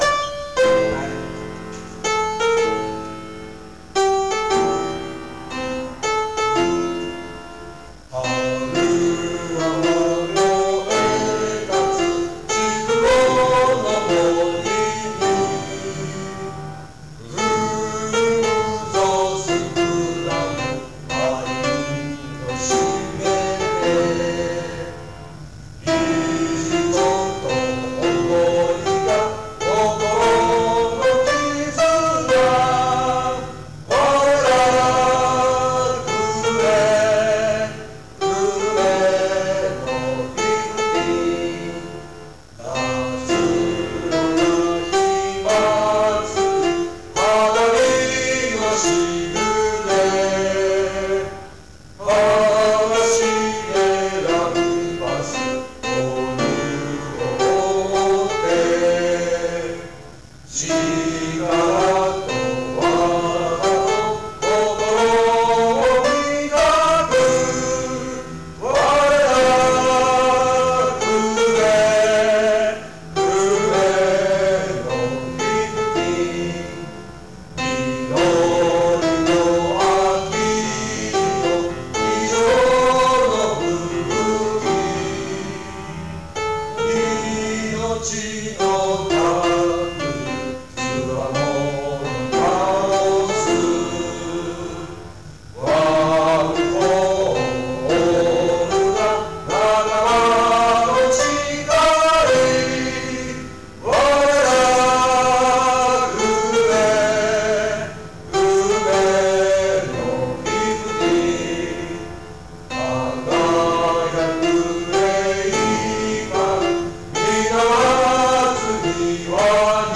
平成14年録音
歌：平成13年度部員　ピアノ